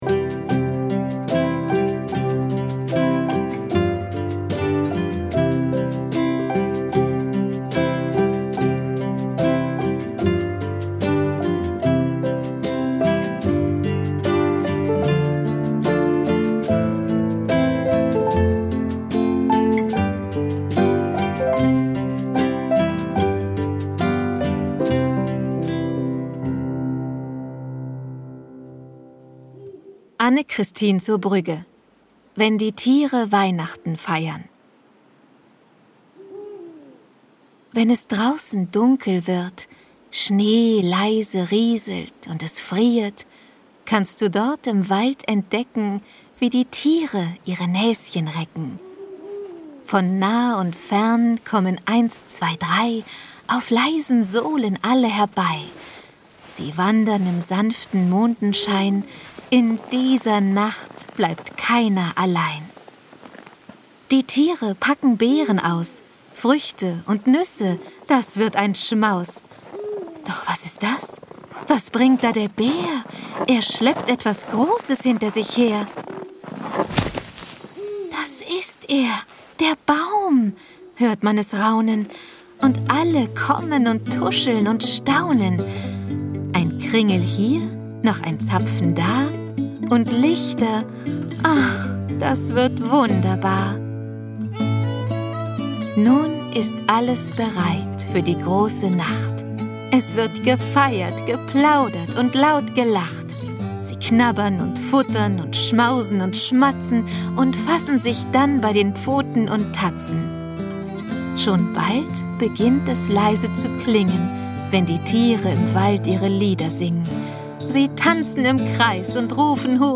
Lese- und Medienproben